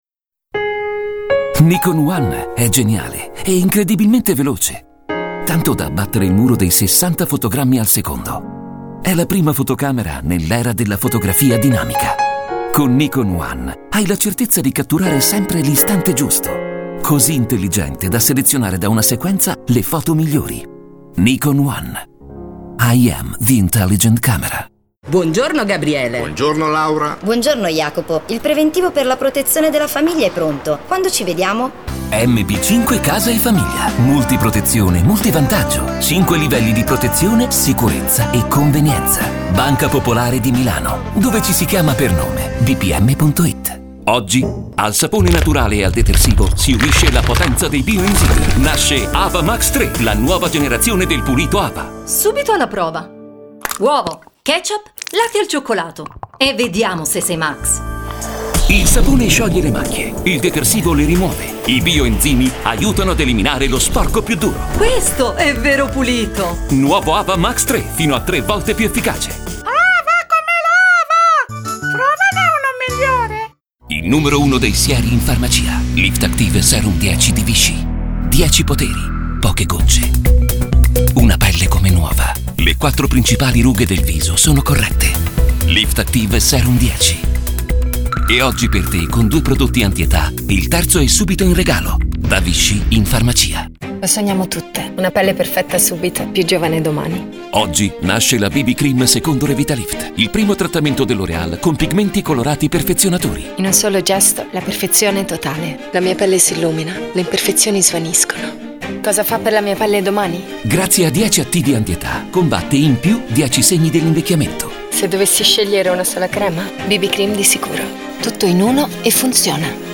Grazie alla duttilità della sua voce registra diversi spot  per i più disparati  settori merceologici , dai detersivi ai giochi per bambini ( giochi preziosi ), dagli spot discografici , alle  pubblicità istituzionali di marchi prestigiosi come L'Oreal , Vichy , Telecom , Wind , Volkwagen , Mentadent, Mondadori  ecc. di cui è da sempre o quasi la voce di riferimento.